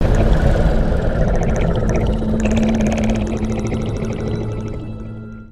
Abaddonroar.mp3